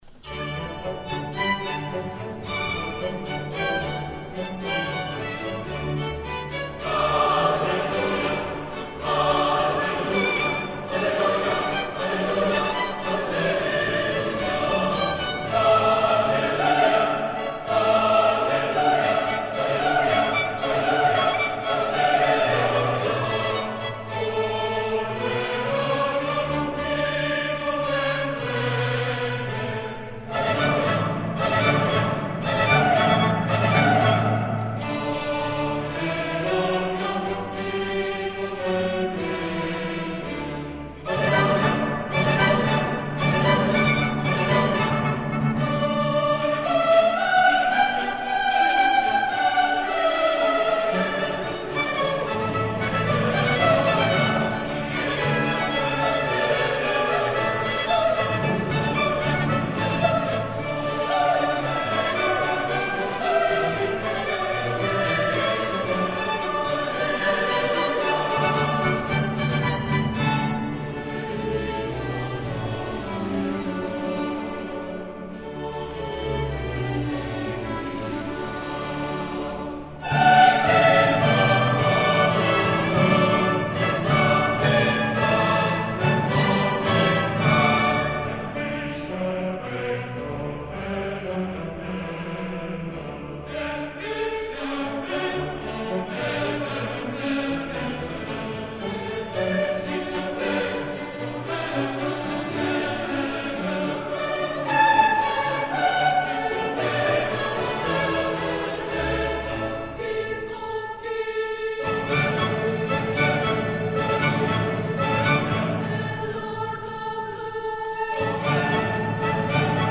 CD1 VOCI E STRUMENTI